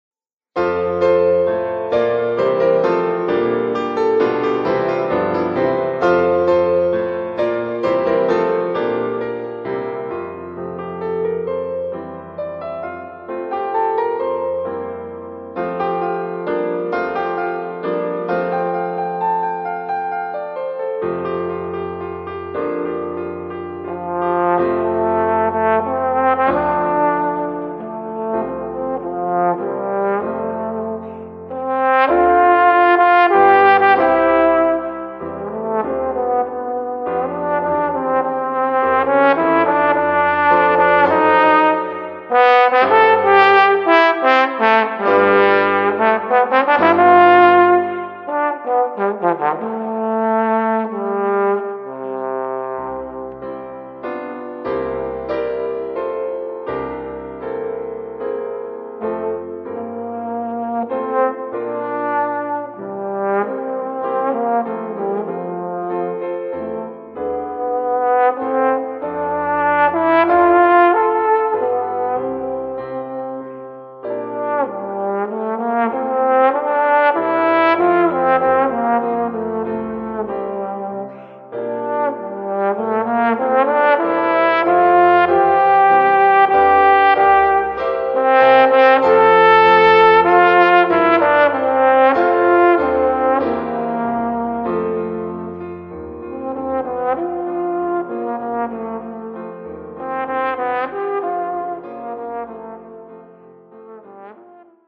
Voicing: Trombone and Piano